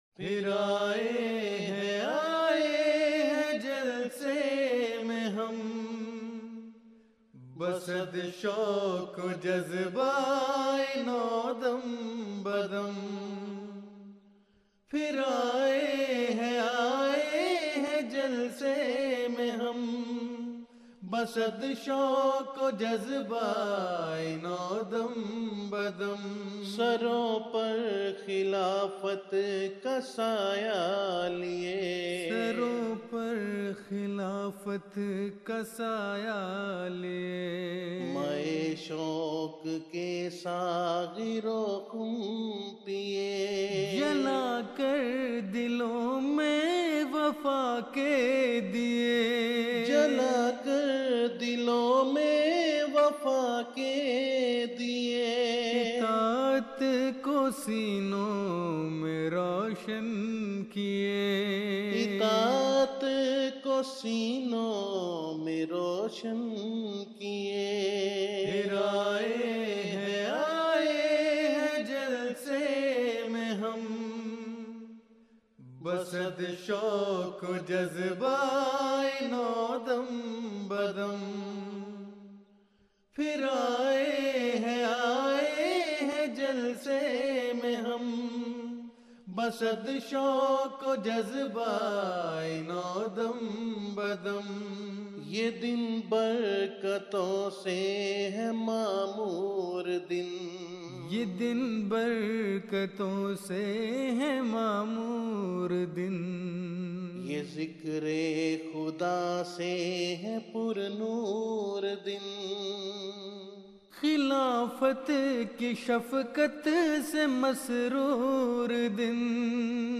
Urdu Poems
Voice: Group
Jalsa Salana Germany 2011